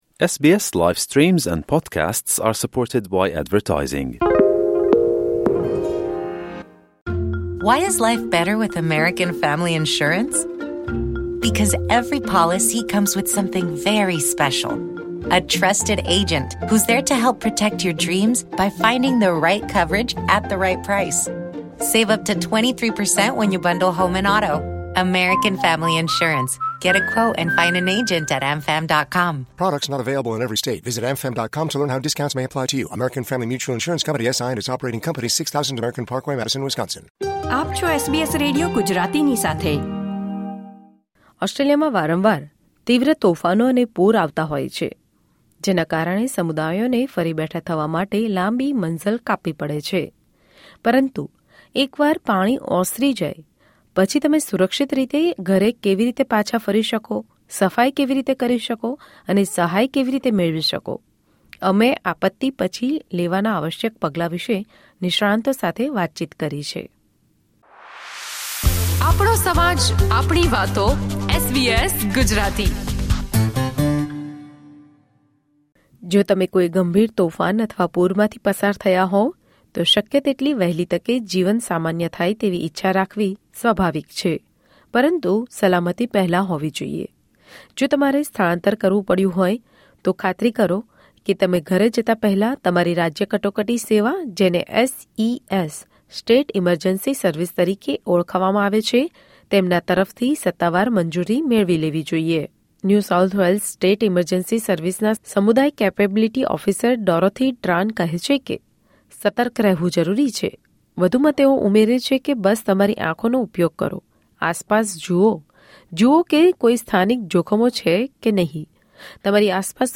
But once the water recedes, how do you return home safely, clean up, and find support? We speak with experts on the essential steps to take after a disaster.